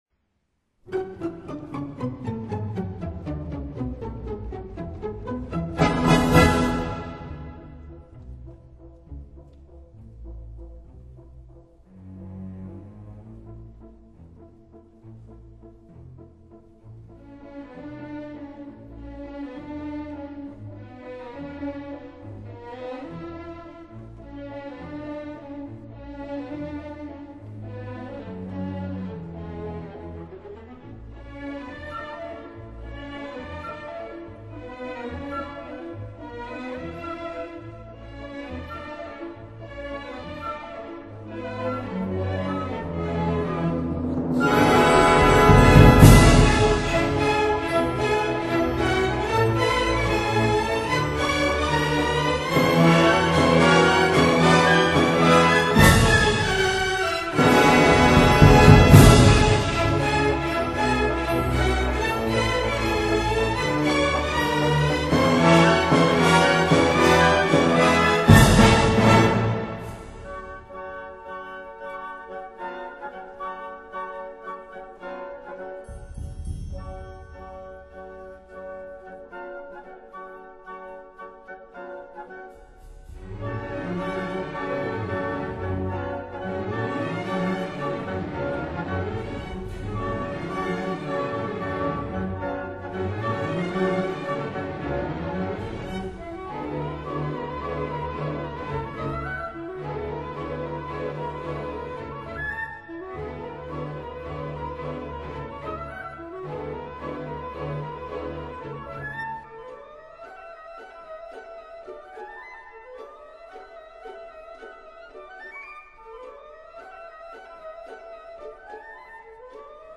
2 圆舞曲(6:45)